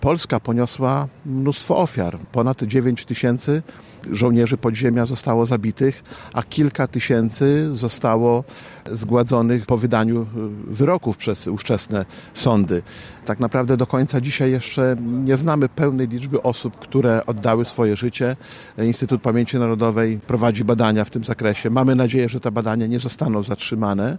Starosta łomżyński – Lech Marek Szabłowski wskazuje, że żołnierze poświęcili swoje życie w słusznym celu: